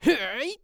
CK蓄力03.wav
CK蓄力03.wav 0:00.00 0:00.65 CK蓄力03.wav WAV · 56 KB · 單聲道 (1ch) 下载文件 本站所有音效均采用 CC0 授权 ，可免费用于商业与个人项目，无需署名。
人声采集素材/男2刺客型/CK蓄力03.wav